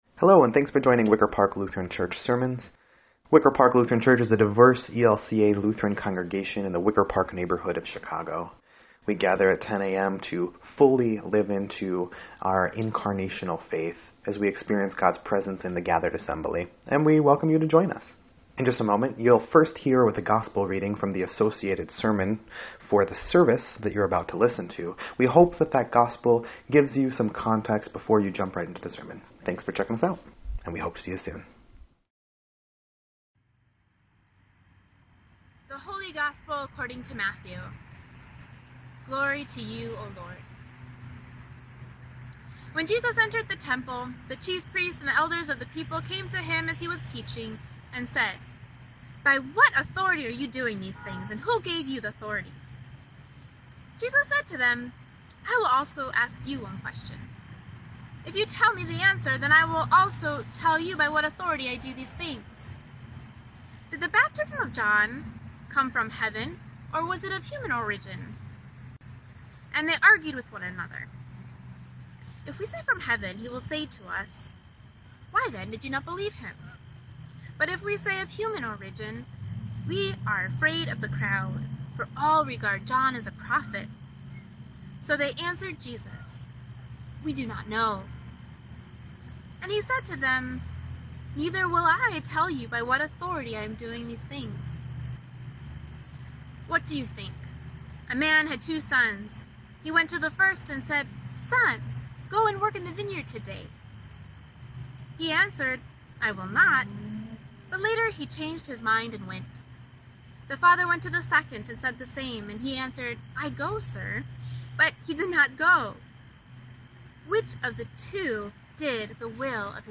9.27.20_Sermon.mp3